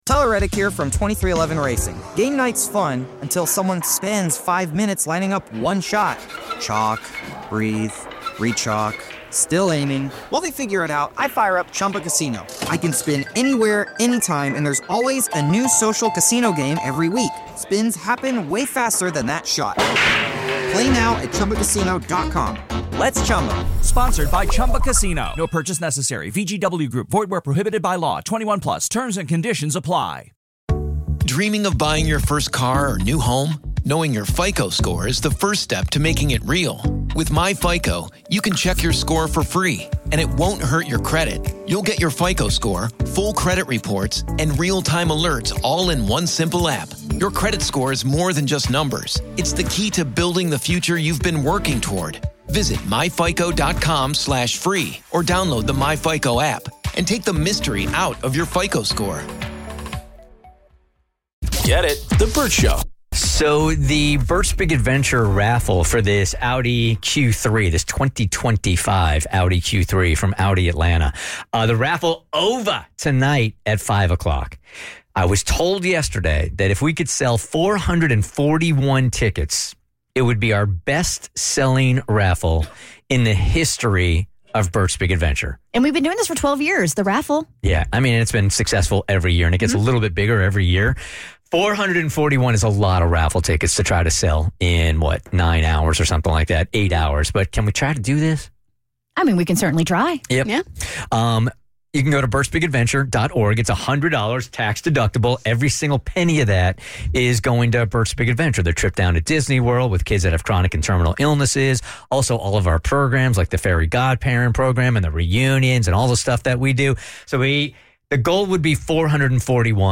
One of our faves is back in studio.